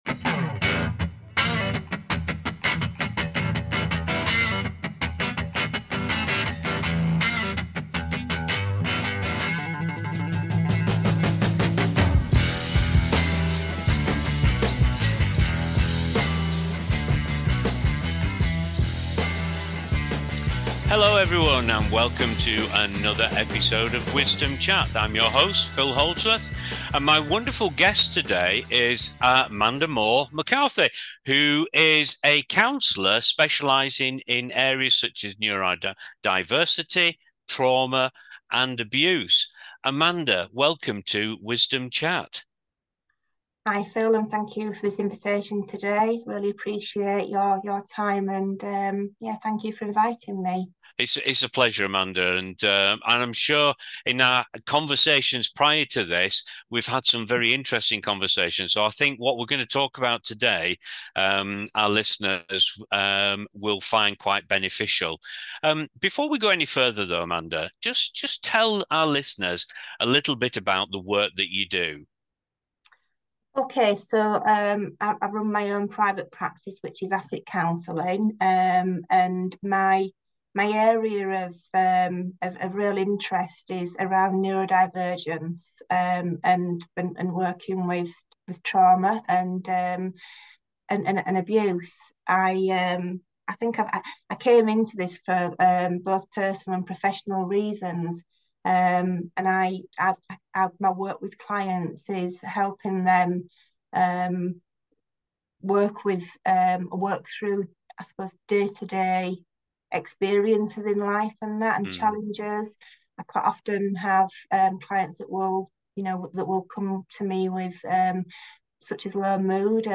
Their insightful dialogue invites listeners to embrace empathy, gratitude, and the transformative power of reaching out to others in need.